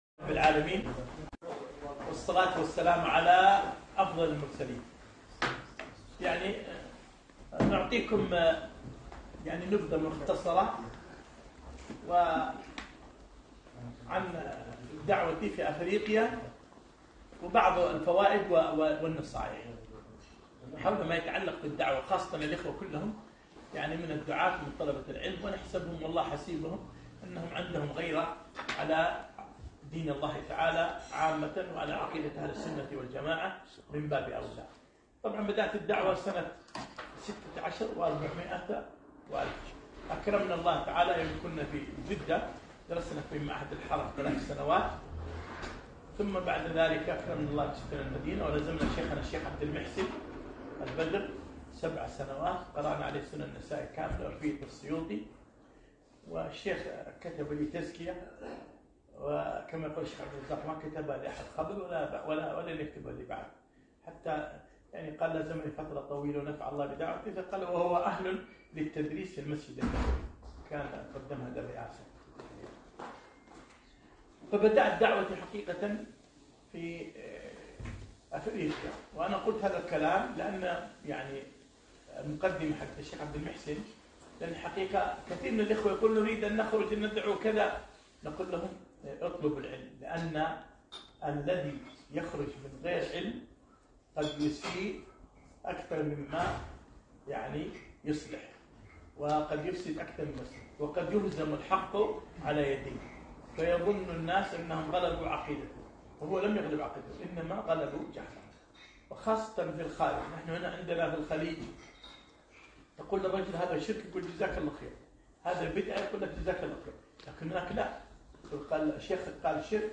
كلمة - فوائد من رحلتي في افريقيا